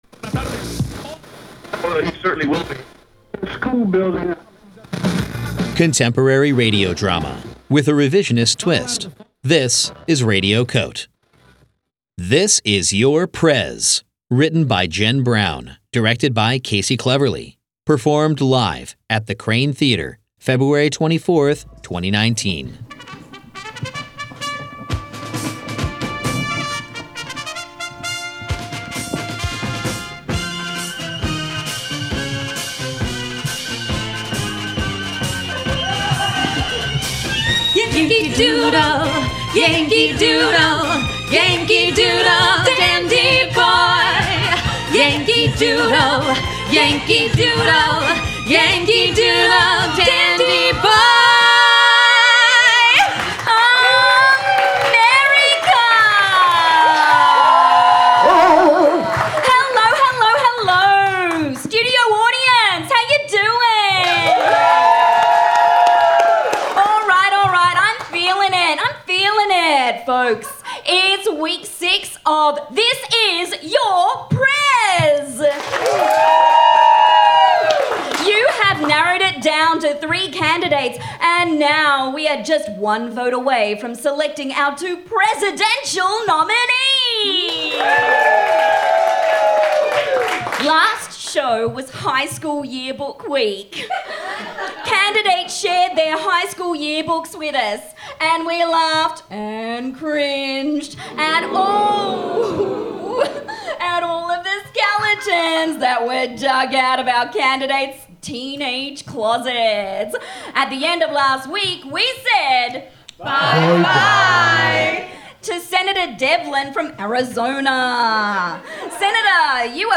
performed for Radio COTE: 24-hour Newsroom in the FRIGID Festival, February 24, 2019